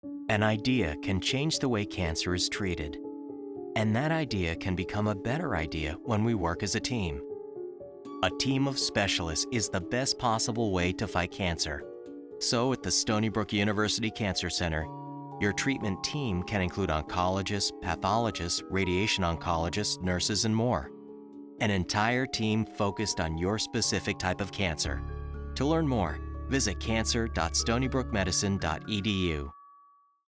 "Teamwork" :30 Radio Spot